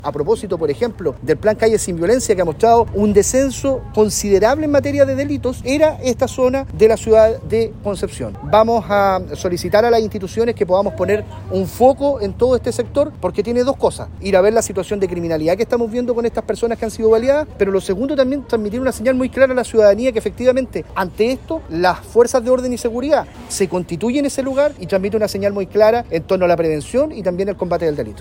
En tanto, el delegado presidencial de la región del Bío Bío, Eduardo Pacheco, aseguró que los delitos habían disminuido.